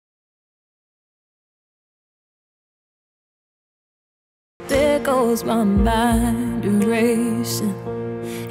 1-woman.WAV